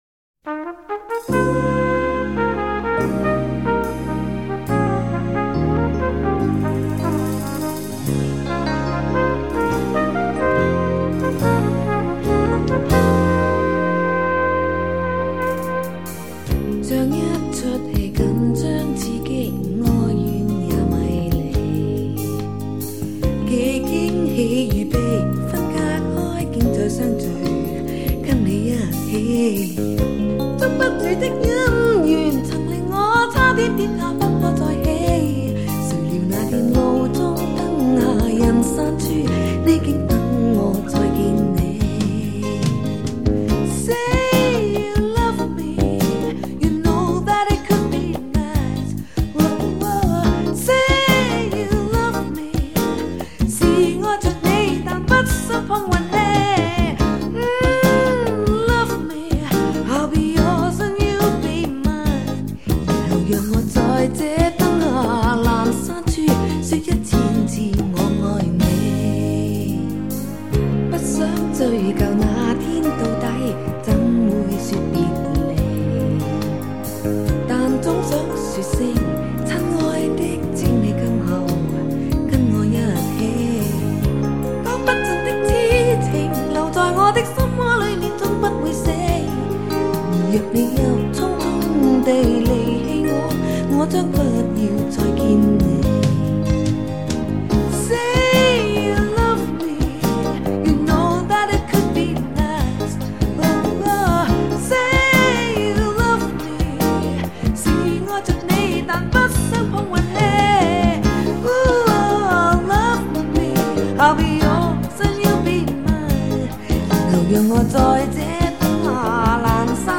划时代顶级发烧母版处理
音色更接近模拟(Analogue)声效
强劲动态音效中横溢出细致韵味